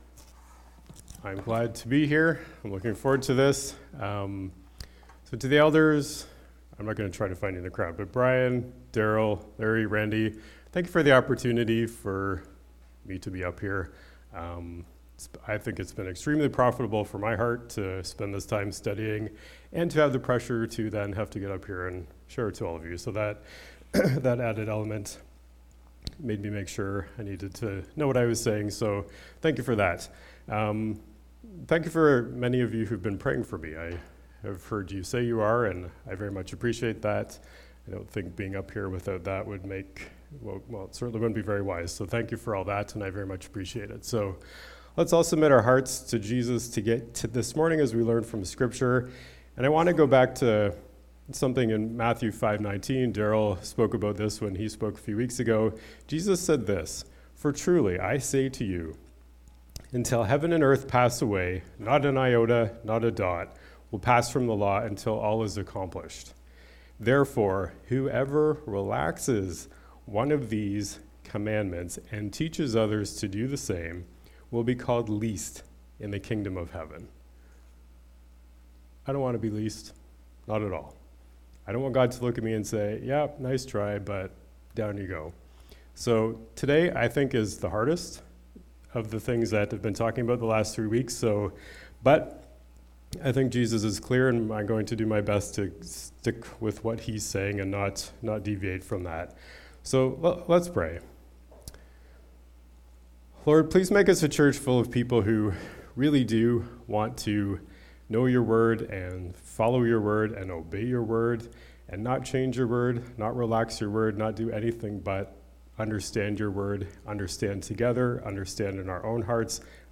Sermon Audio and Video